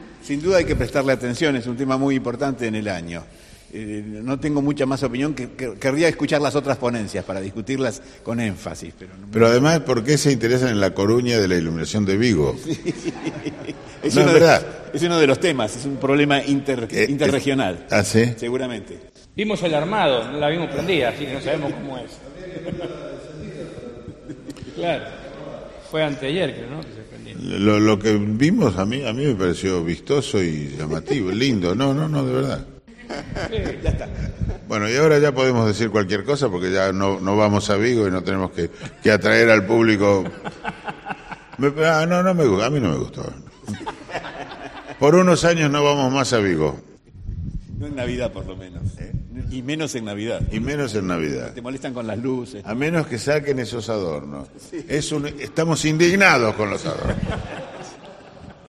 Les Luthiers improvisan una "tertulia radiofónica" sobre la iluminación navideña de Vigo